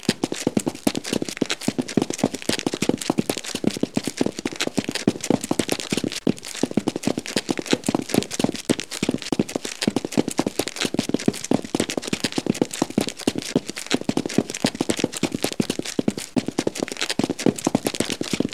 Звуки аудитории, толпы
Шум бегущих людей